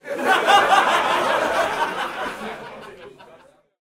teenageCrowdLaugh.ogg